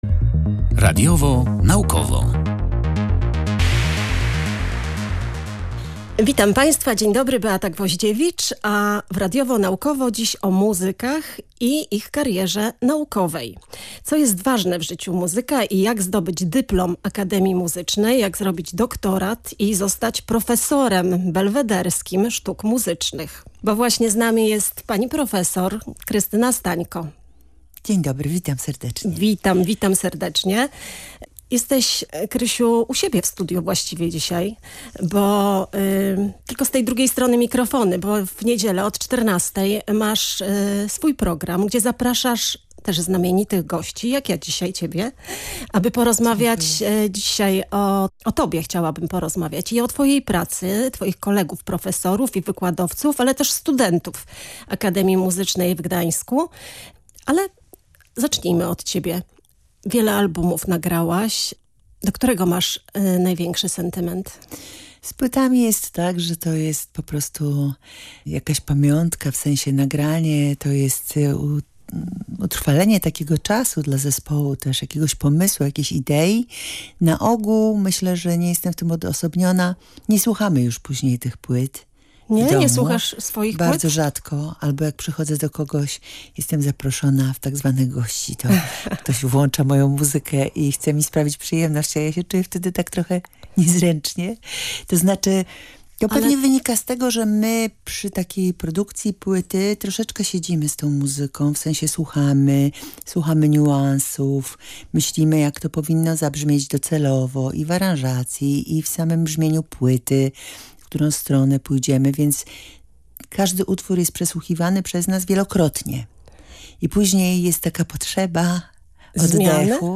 W audycji „Radiowo-Naukowo” rozmawialiśmy o muzykach i ich karierach naukowych. Co jest ważne w ich życiach, jak zdobyć dyplom akademii muzycznej, jak zrobić doktorat i zostać profesorem belwederskim sztuk muzycznych?